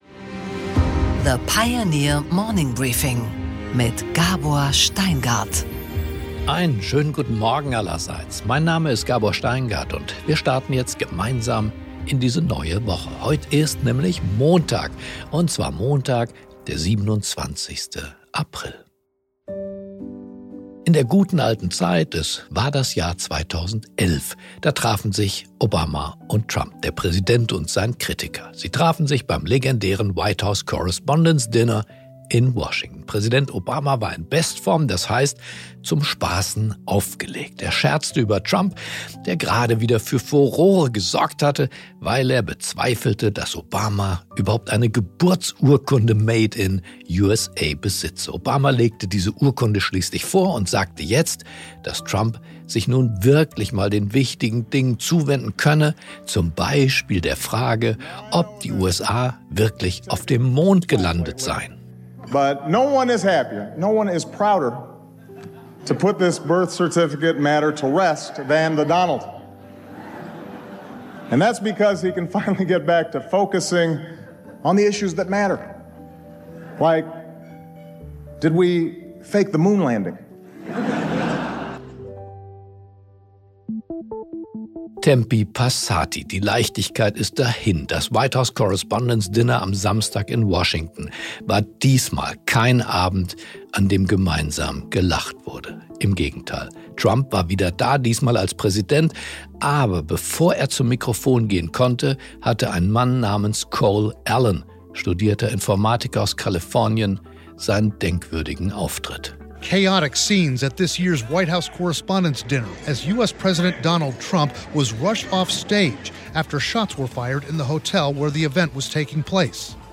Gabor Steingart präsentiert das Morning Briefing.